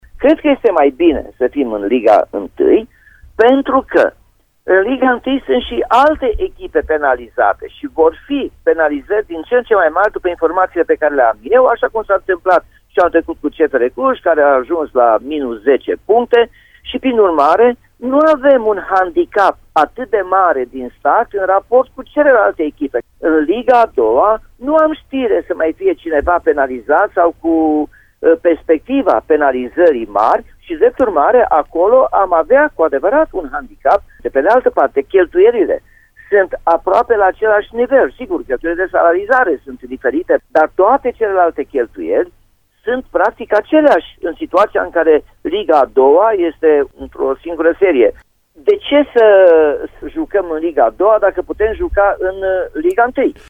Primarul Timișoarei, Nicolae Robu, a declarat ieri, la postul nostru de radio, că este hotărât să sesizeze DNA-ul dacă Rapidul va fi primit în liga I de fotbal în dauna lui ACS Poli.